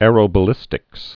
(ârō-bə-lĭstĭks)